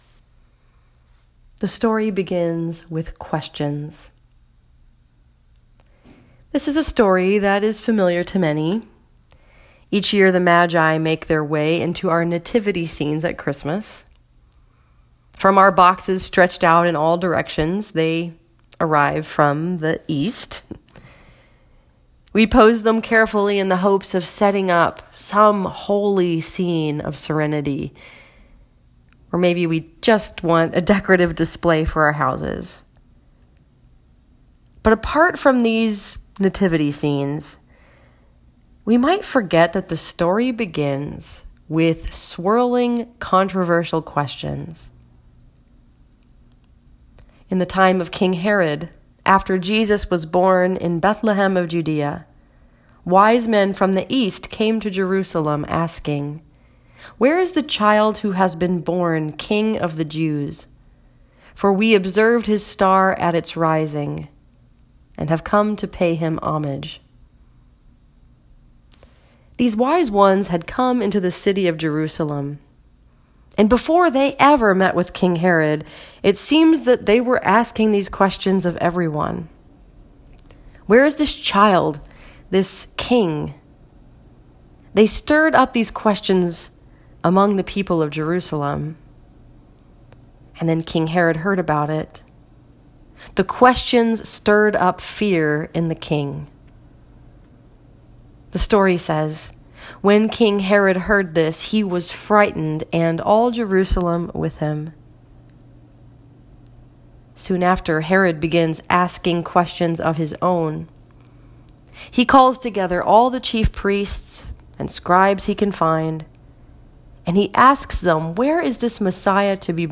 This sermon was preached at Lincoln Park Presbyterian Church in Lincoln Park, Michigan and was focused upon the story that is told in Matthew 2:1-12.